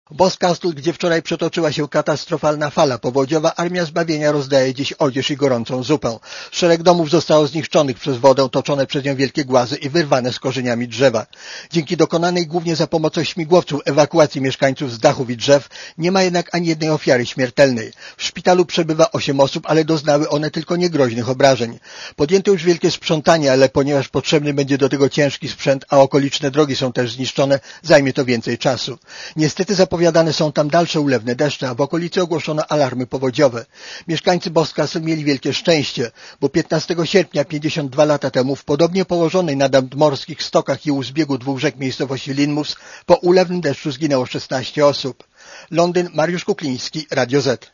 Korespondencja z Londynu